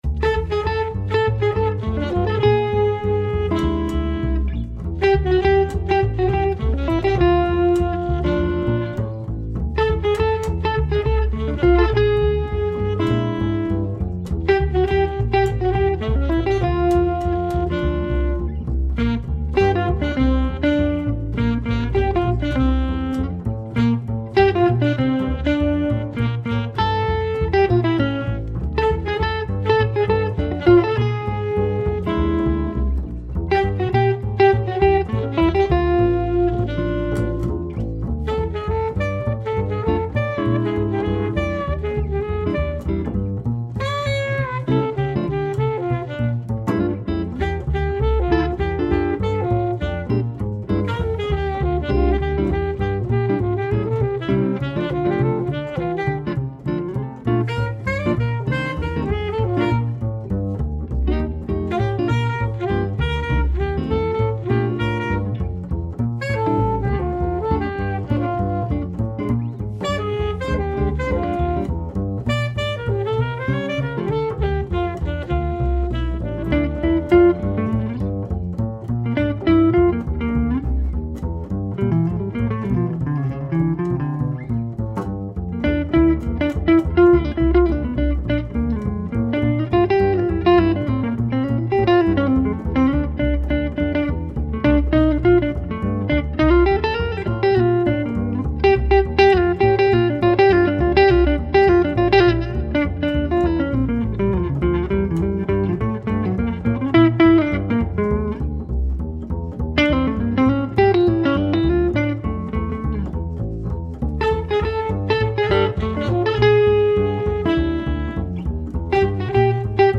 Melodisk og swingende jazz.
jazzguitar, saxofon og kontrabas
• Jazzband